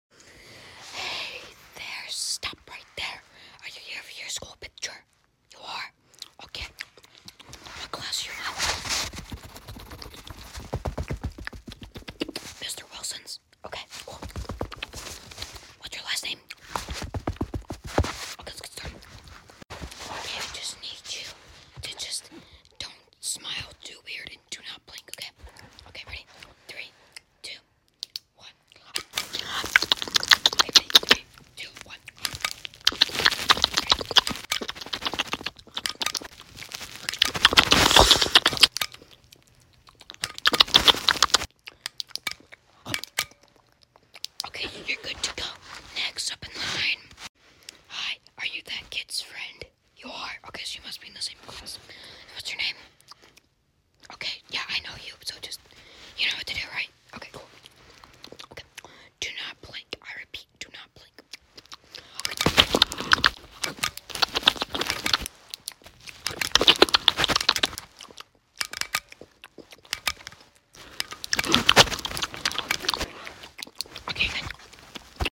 ASMR school picture day! sound effects free download